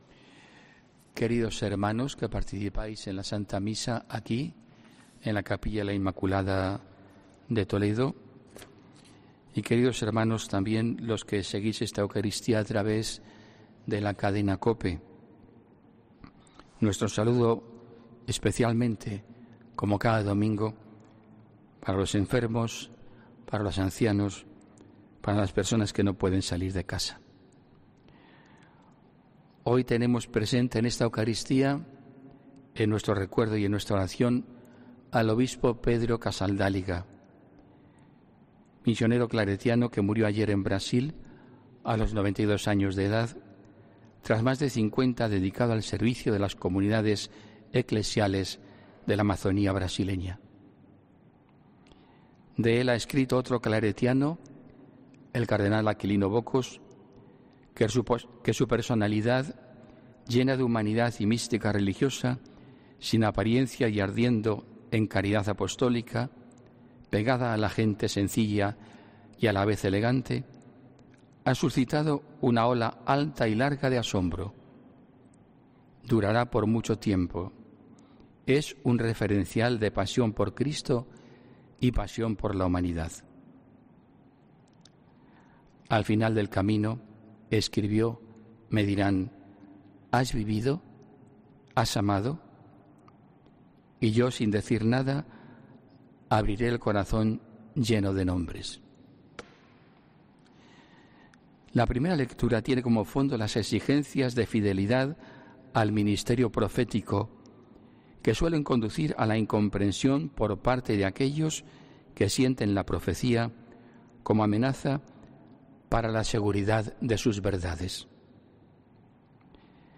Homilía